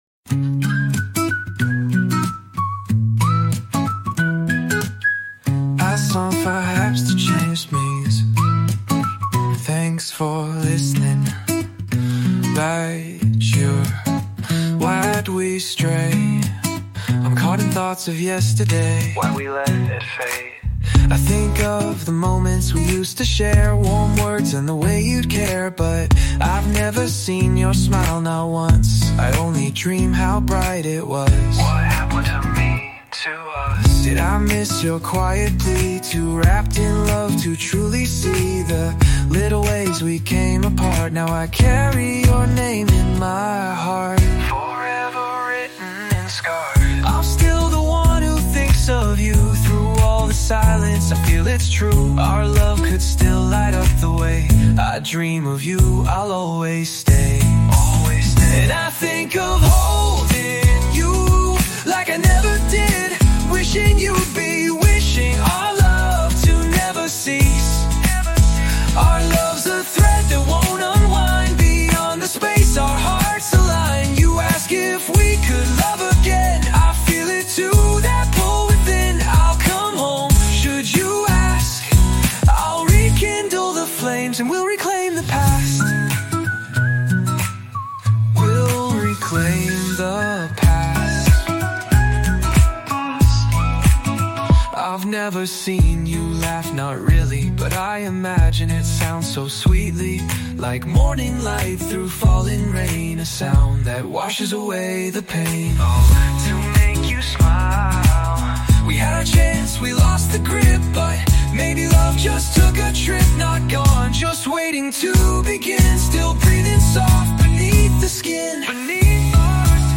Chill Lo-fi Beats for Focus and Late Night Work